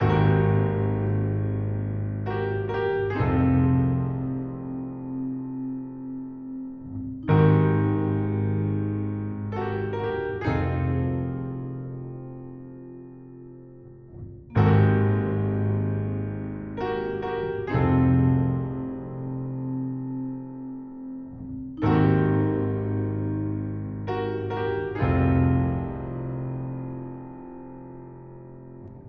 孤独的钢琴
描述：8个无缝小节的忧郁的钢琴曲调。
Tag: 66 bpm Chill Out Loops Piano Loops 4.90 MB wav Key : F